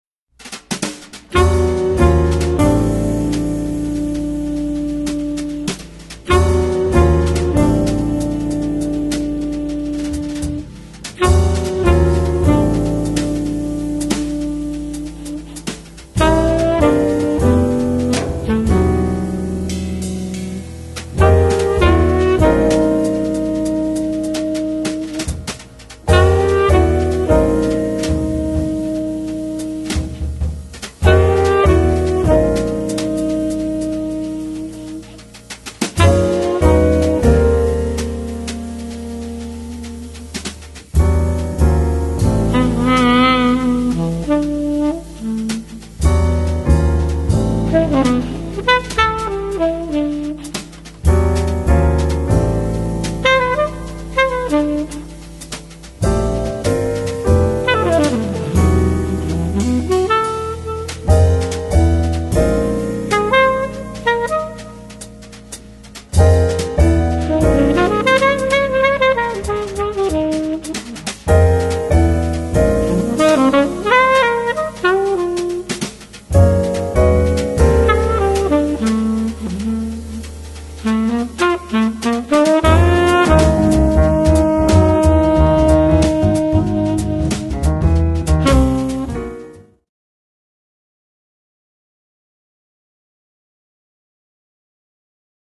Drums
Piano & Organ
Guitar
Trumpet & Flugelhorn
Bass
Percussion